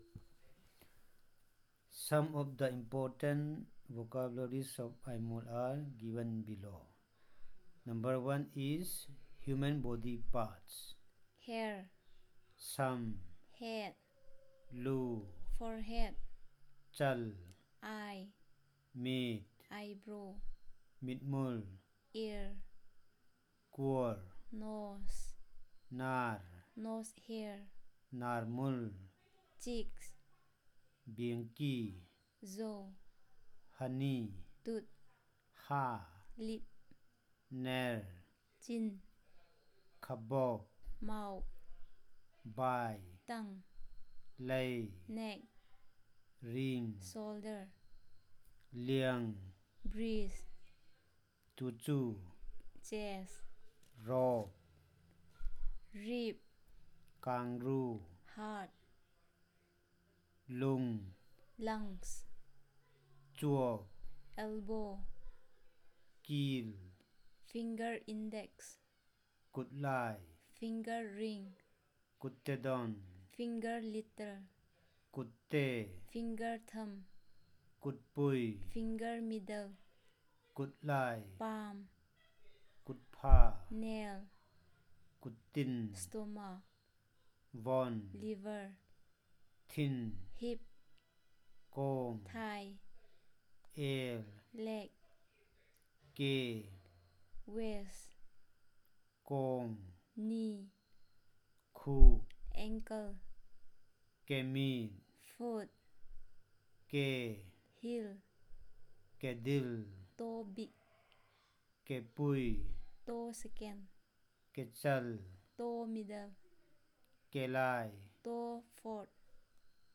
Elicitation of multi-domain words
NotesThis is an audio of interviewing Aimol consultant for eliciting words for Human and animal Body parts, Color terms and Kinship terms.